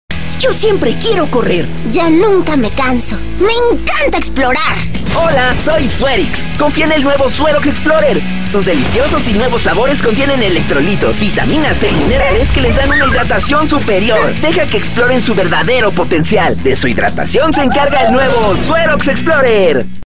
Male
Eloquent
Friendly
Energetic
Warm
Television Spots
Words that describe my voice are Eloquent, Warm, Friendly.